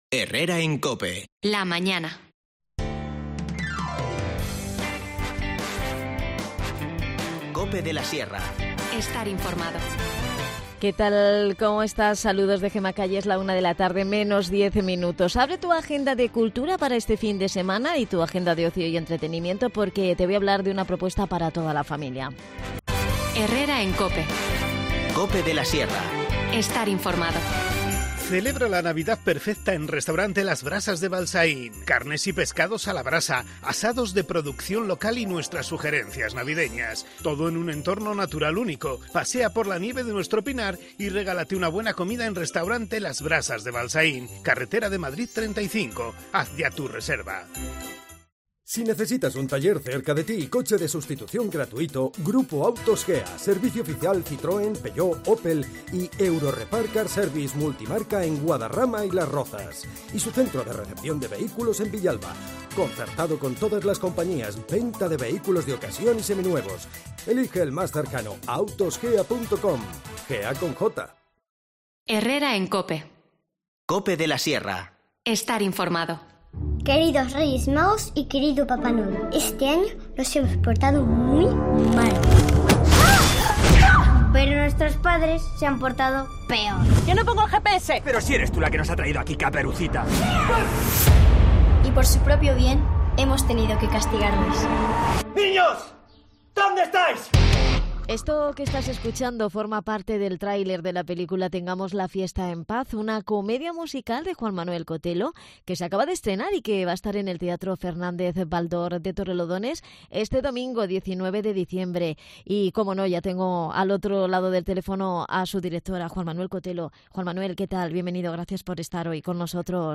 Charlamos con su director, Juan Manuel Cotelo.